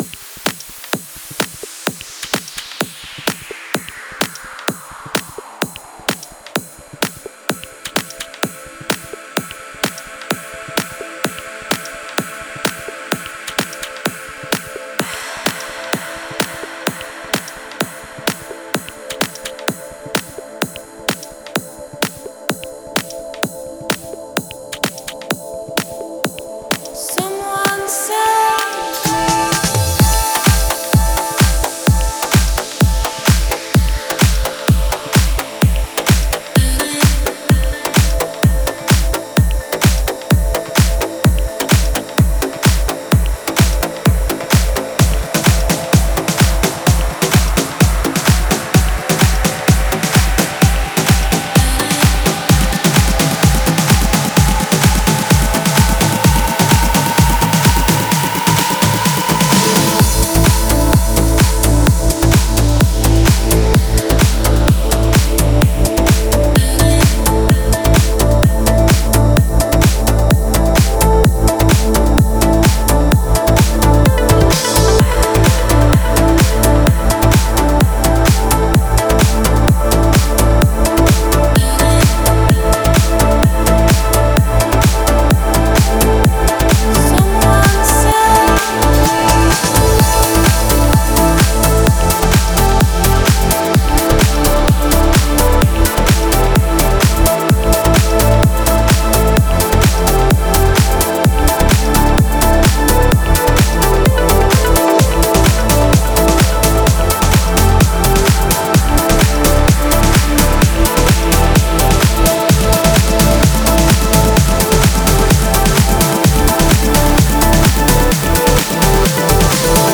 Стиль: Progressive House / Progressive Trance